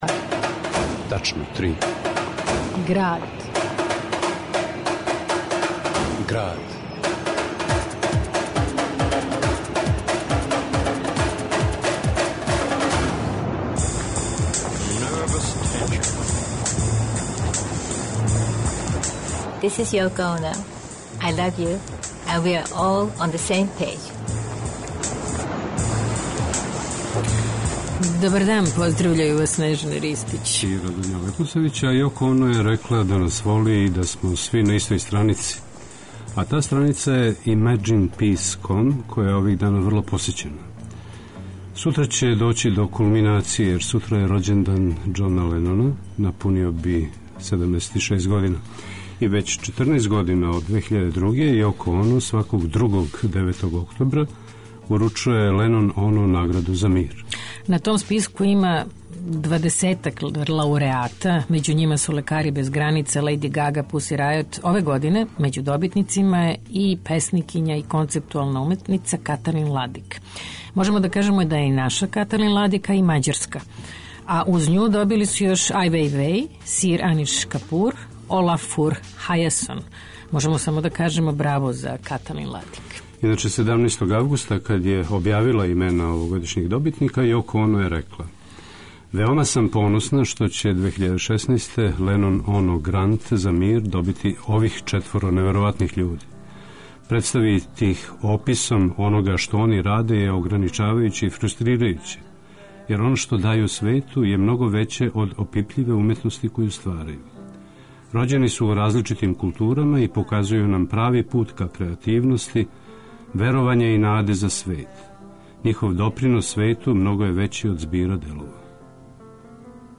У Граду, Каталин Ладик - песникиња, концептуална уметница и глумица - говори о себи и свом раду. Снимак је начињен 2013. на Хвару, а ЛенонОно награда за мир за 2016 биће јој уручена испред Светлосне куле на Исланду.